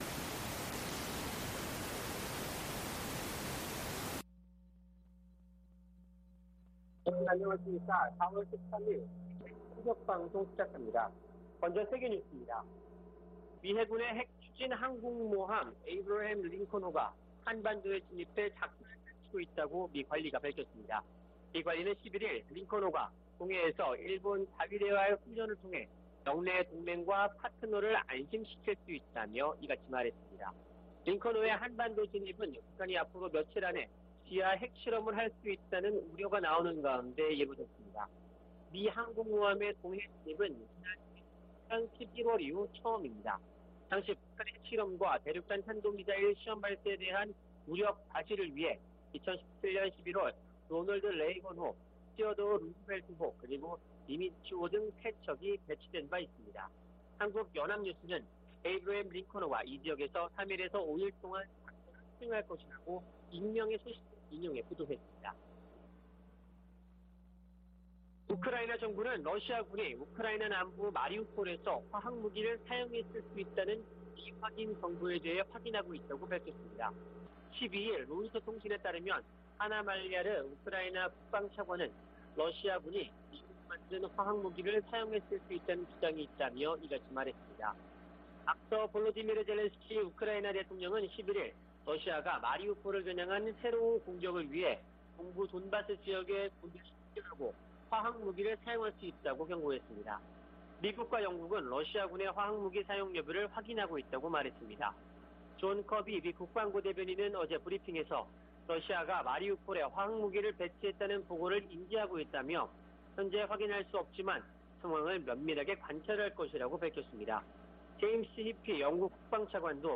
VOA 한국어 '출발 뉴스 쇼', 2022년 4월 13일 방송입니다. 미국의 핵 추진 항공모함 에이브러햄 링컨함이 한반도 동해 공해상에 전개됐습니다.